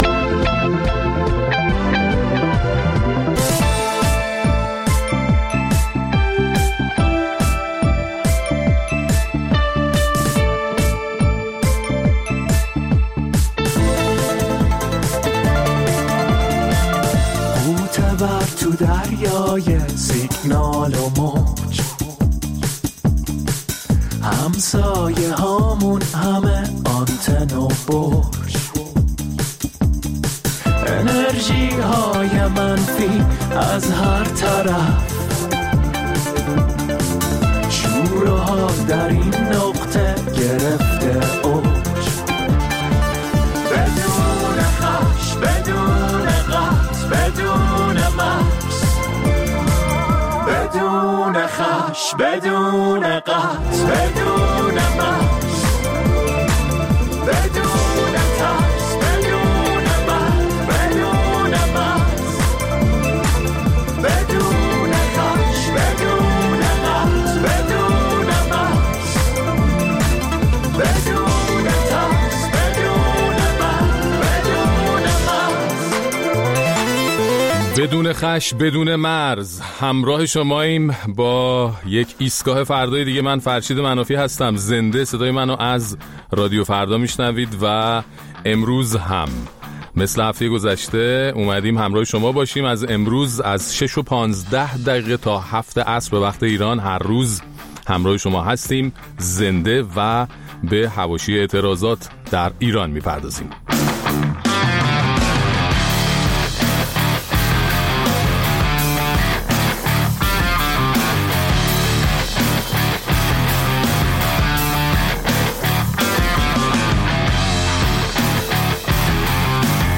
در این ایستگاه فردای ویژه، با توجه به ادامه اعتراضات سراسری در ایران، به حواشی و متن این اعتراضات می‌پردازیم و نظرات و مشاهدات شنوندگان برنامه را هم در این باره می‌شنویم.